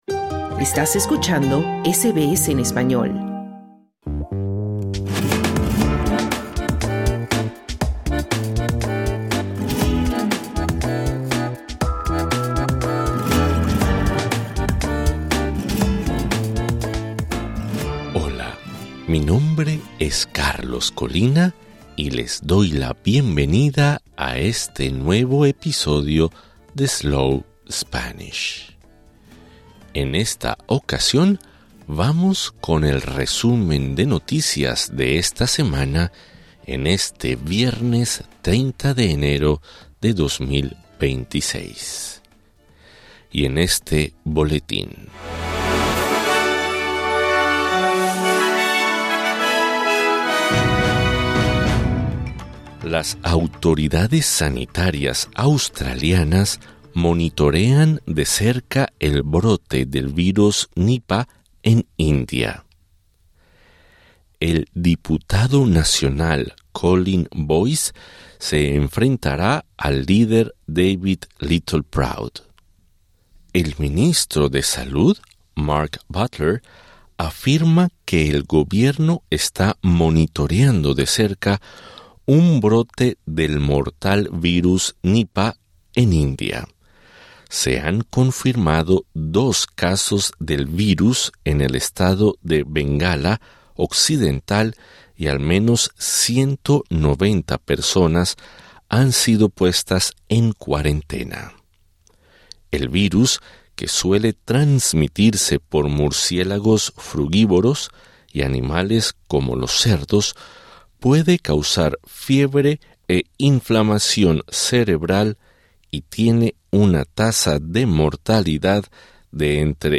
Welcome to SBS Slow Spanish, a podcast designed in Australia specifically for those interested in learning the second most spoken language in the world. This is our weekly news flash in Spanish for January 30, 2026.